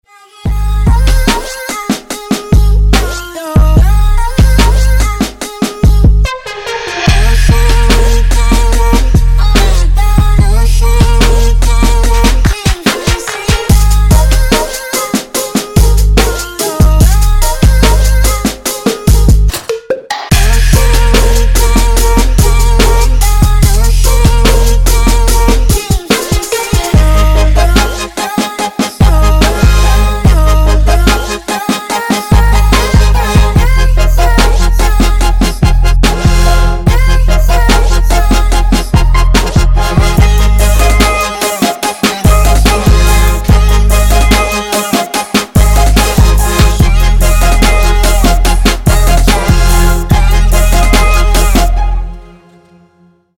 Trap
future bass